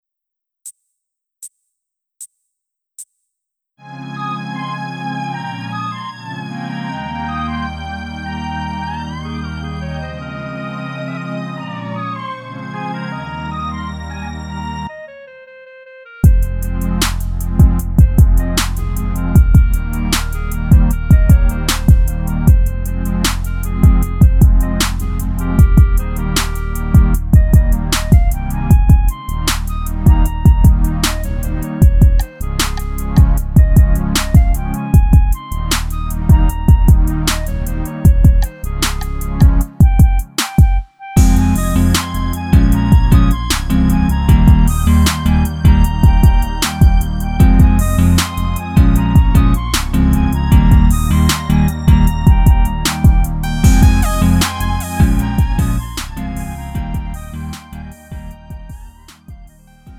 음정 원키 2:44
장르 구분 Lite MR